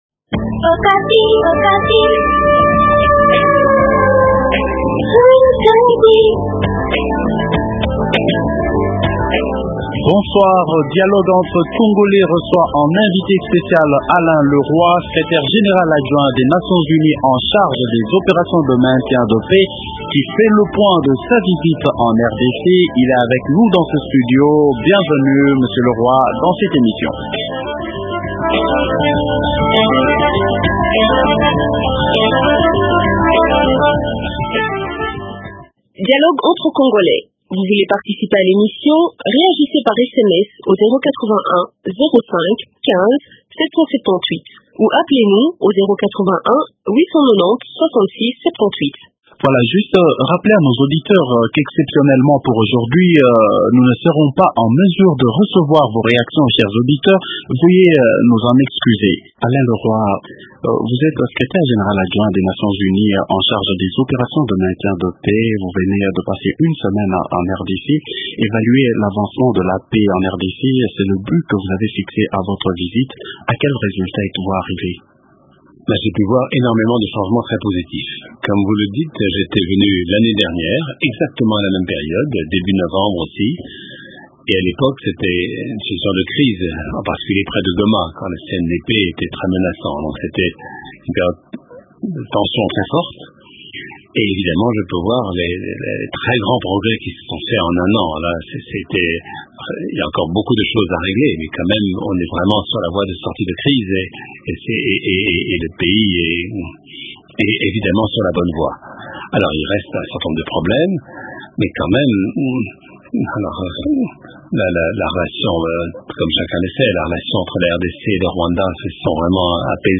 Dialogue entre congolais reçoit en invité spécial Alain Le Roy, secrétaire général adjoint des Nations Unies en charge des opérations de maintien de la paix. Il fait le point de sa visite en RDC. | Radio Okapi